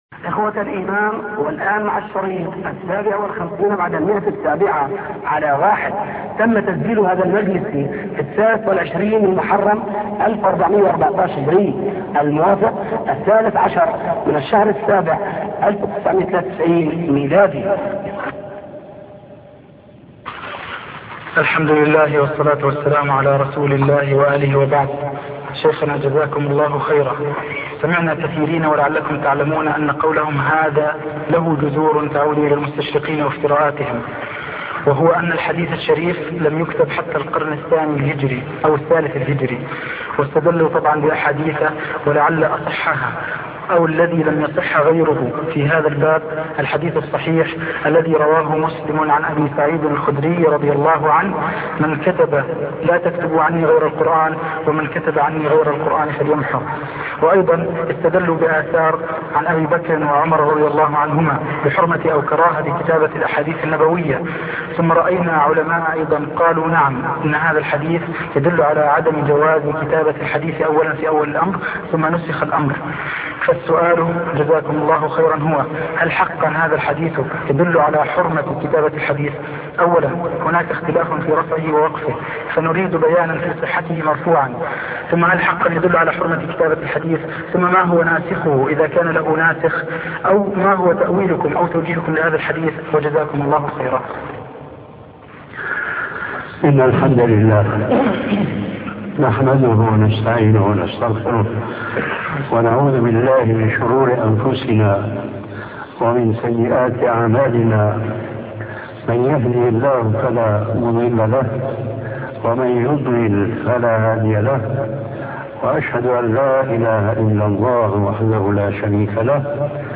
محاضرة الرد على المستشرقين 1 الشيخ محمد ناصر الدين الألباني
الرد على المستشرقين 1 فضيلة الشيخ محمد ناصر الدين الألباني الأحد 31 ديسمبر 1899 الموافق لـ : 27 شعبان 1317 6.6M 00:29:01 منهج 158 123 باقي محاضرات الشيخ كل المحاضرات سماع المحاضرة تحميل المحاضرة شارك